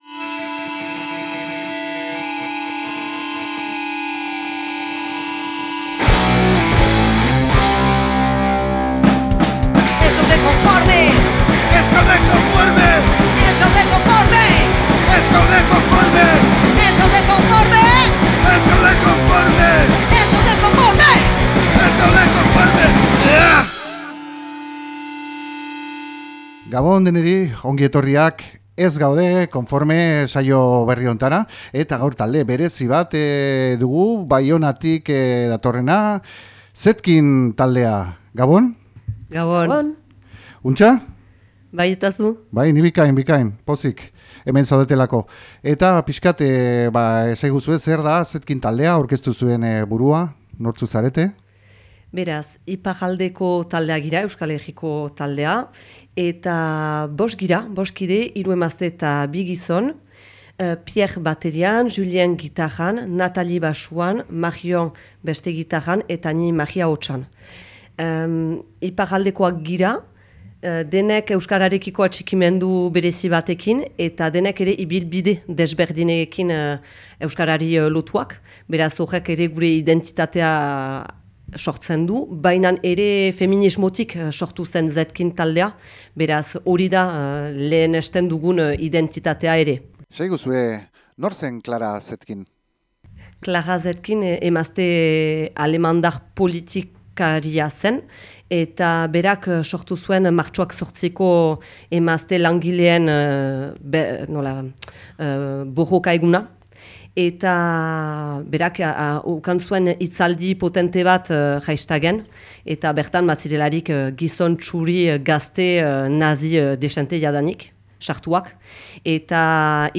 ZETKIN, Baionako taldea dugu, edukiz betea den elkarrizketa baten ondotik, zuzenezko emanaldi engaiatua eskeiniko digutelarik.